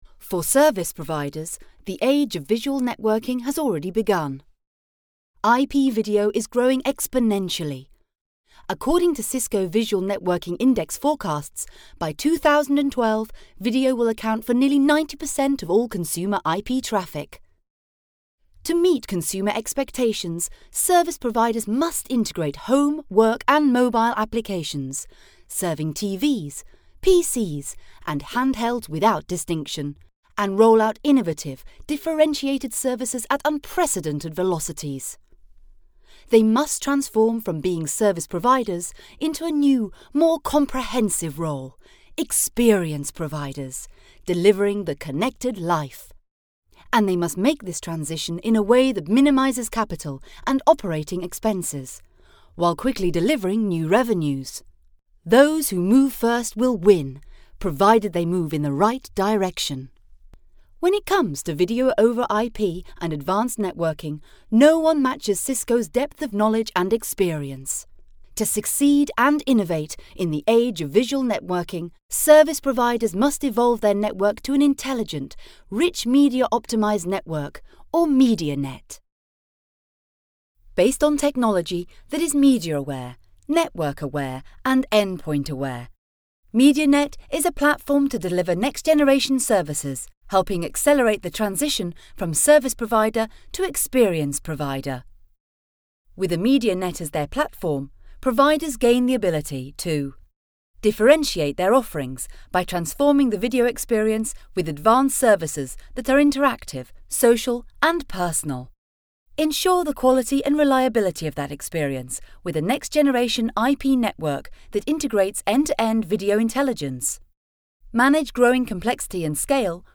She offers a fast and efficient service with her professional home studio.
britisch
Sprechprobe: Industrie (Muttersprache):